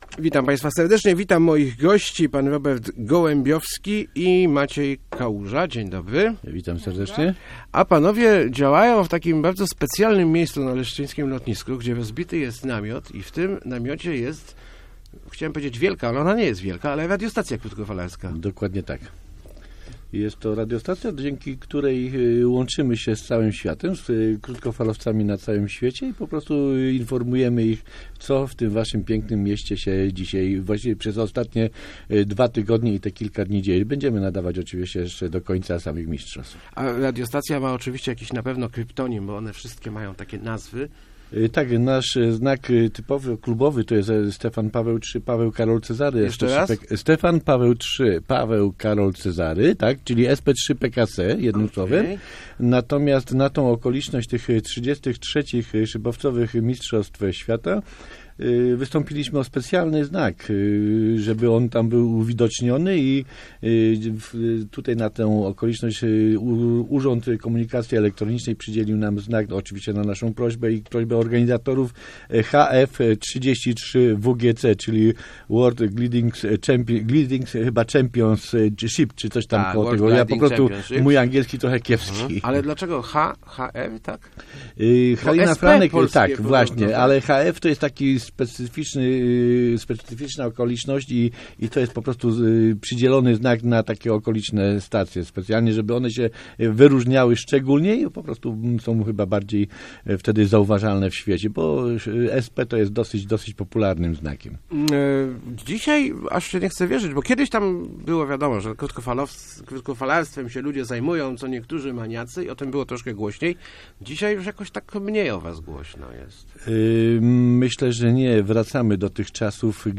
Start arrow Rozmowy Elki arrow Krótkofalowcy nadają z lotniska